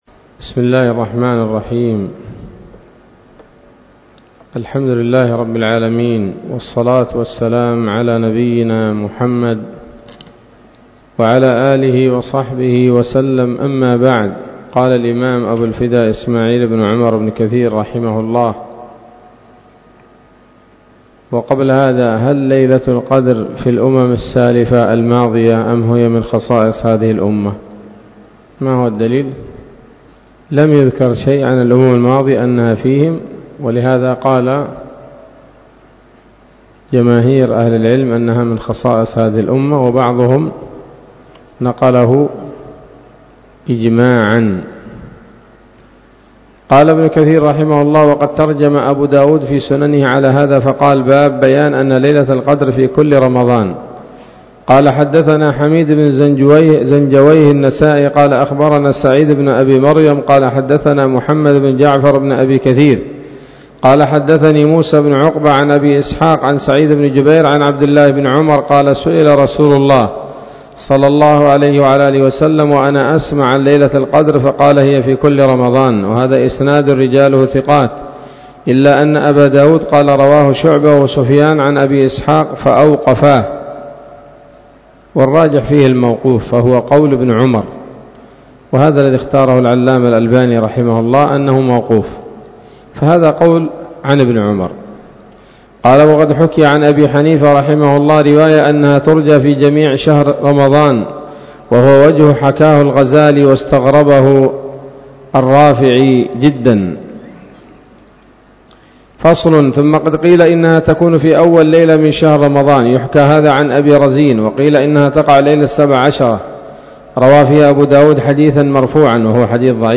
الدرس الثالث من سورة القدر من تفسير ابن كثير رحمه الله تعالى